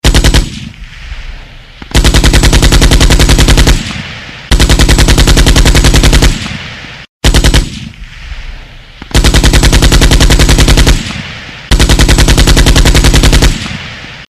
Стрельба из пулемета